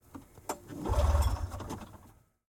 polaris_indy_trail_deluxe_t15_ext_start_try_fail_XY_RSM191.ogg